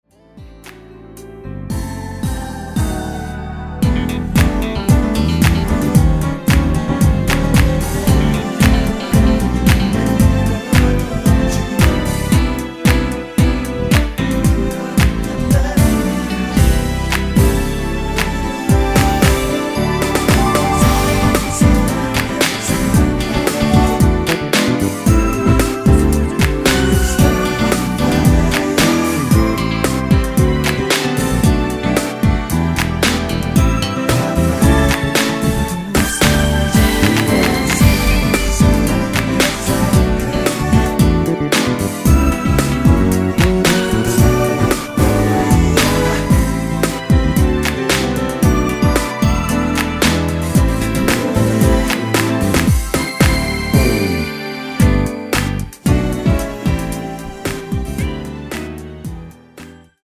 코러스 포함된 MR 입니다.(미리듣기 참조)
◈ 곡명 옆 (-1)은 반음 내림, (+1)은 반음 올림 입니다.
앞부분30초, 뒷부분30초씩 편집해서 올려 드리고 있습니다.